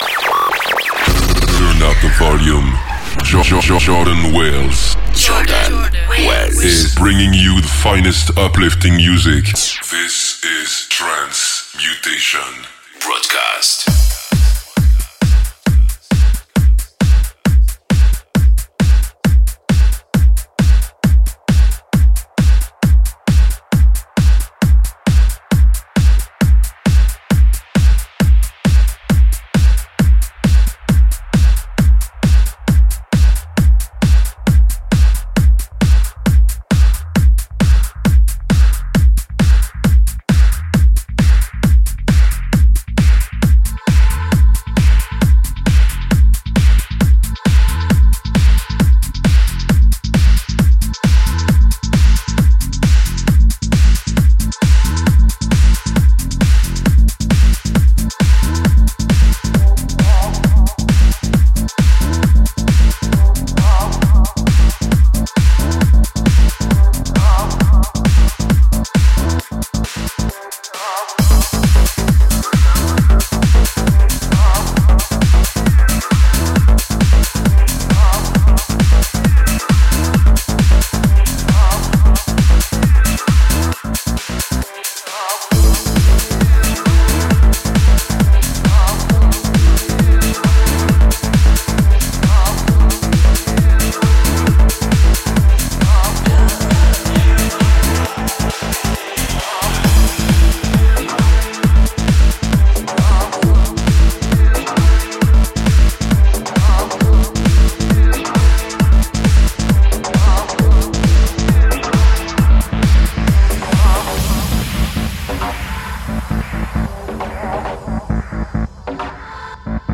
uplifting trance